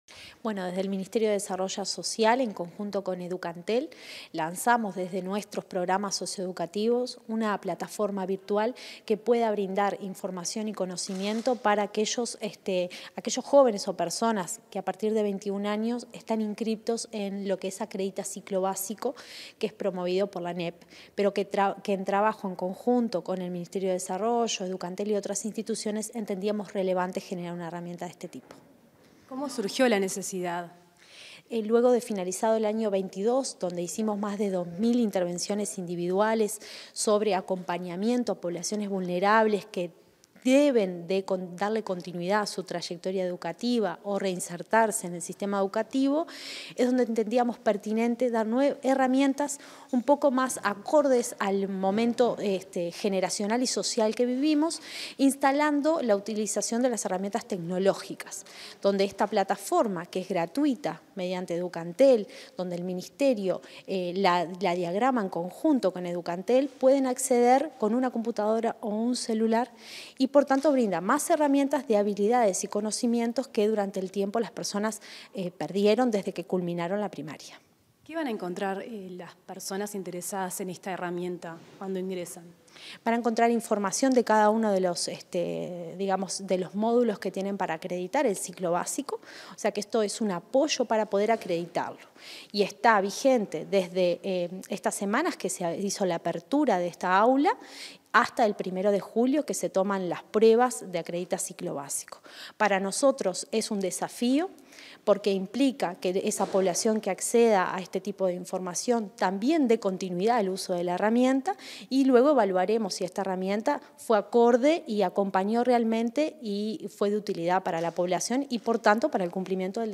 Entrevista a la directora nacional de Desarrollo Social del Mides, Cecilia Sena